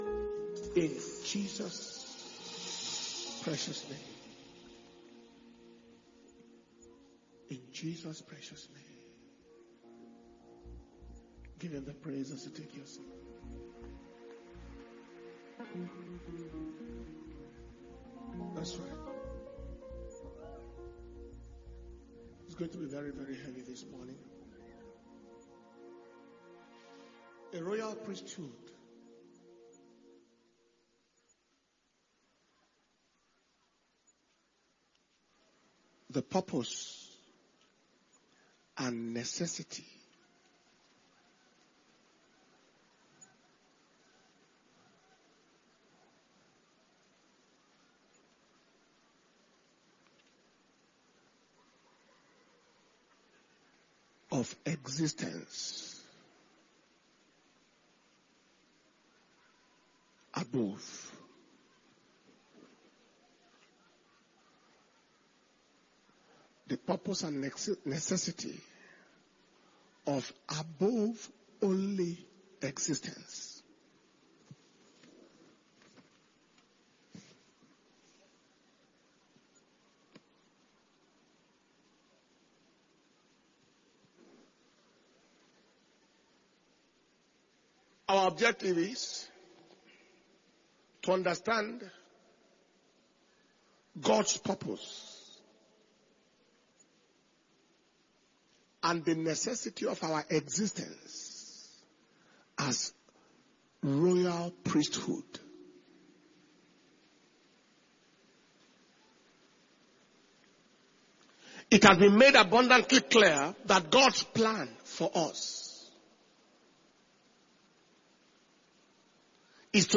A Royal Priesthood – The Purpose And Necessity Of Above Only Existence mp3 By Dr Paul Enenche - Kingdom Power And Glory World Conference